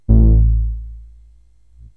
SYNTH BASS-2 0016.wav